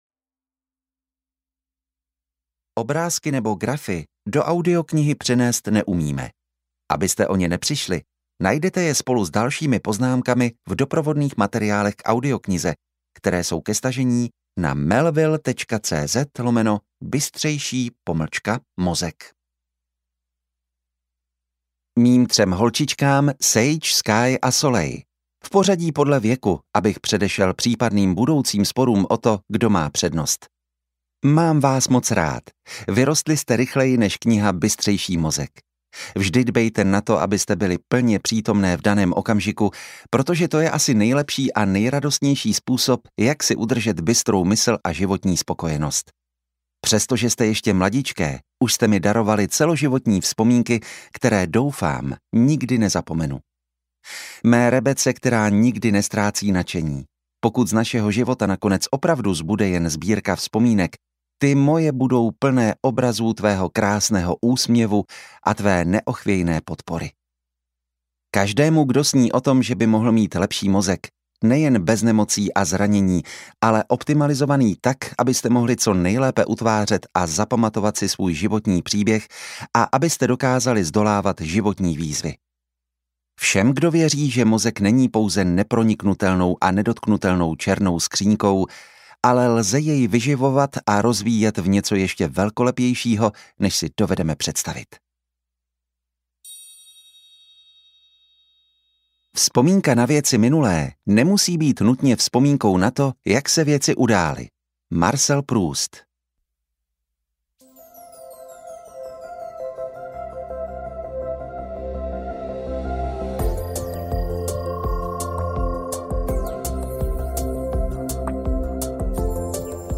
Bystřejší mozek audiokniha
Ukázka z knihy